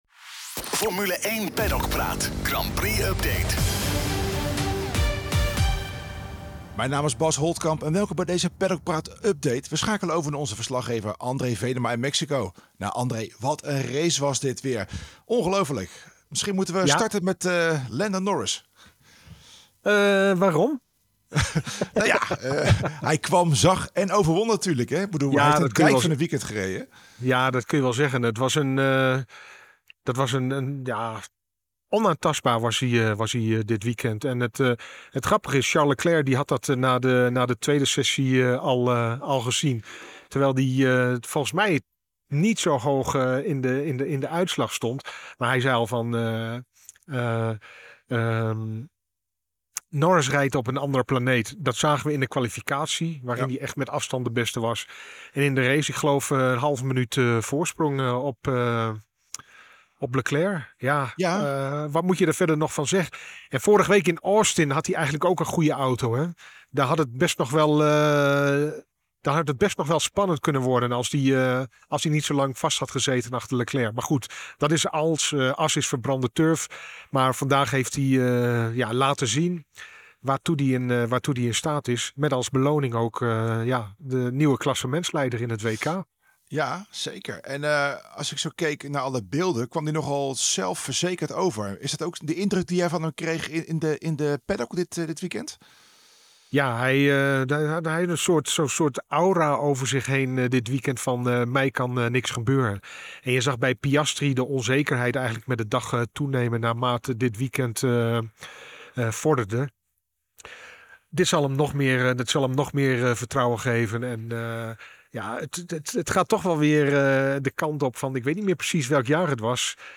blikken we vanuit de paddock in Mexico-Stad terug op de Grand Prix van Mexico. Lando Norris pakte zijn allereerste zege en neemt daarmee de leiding in het WK over, terwijl teamgenoot Oscar Piastri niet verder kwam dan P5. Max Verstappen haalde opnieuw het maximale eruit en finishte als derde, vlak achter Charles Leclerc.